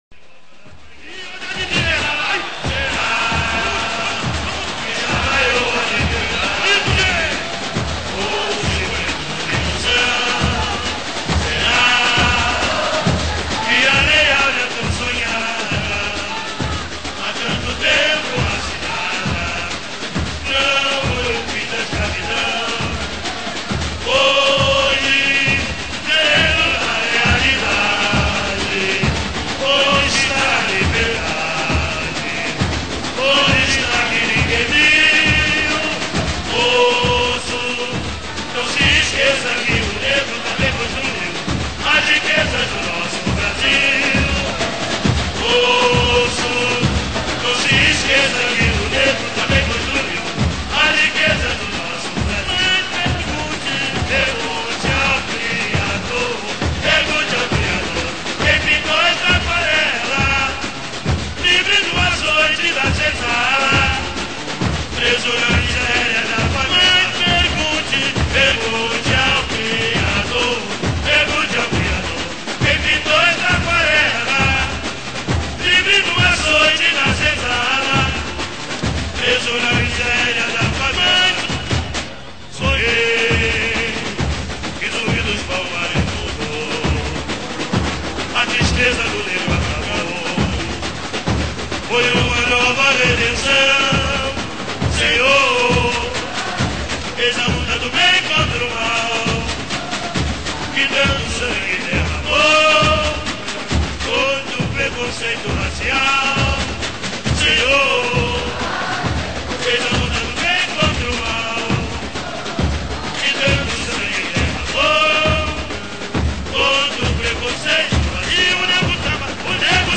(ao vivo)